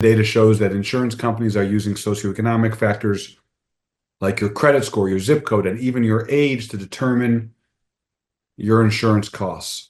During a virtual town hall last month, Giannoulias said auto insurance rates jumped 18% in Illinois last year.